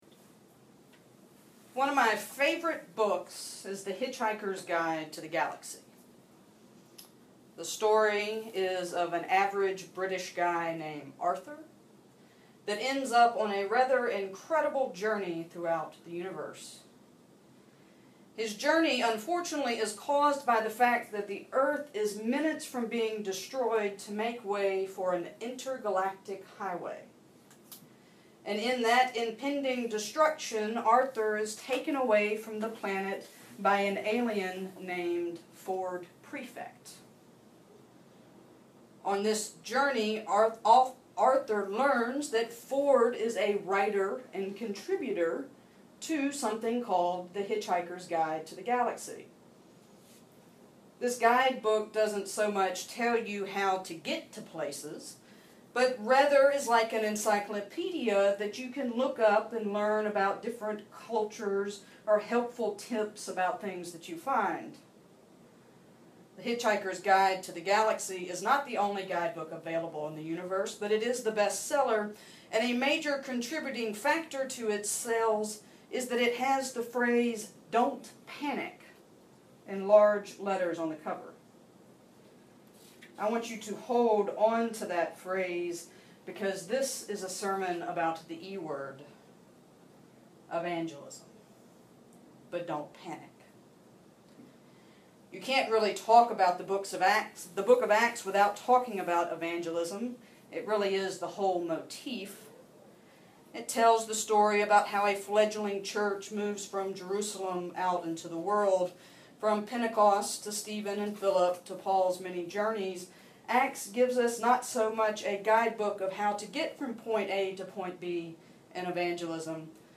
A Sermon by the Rev.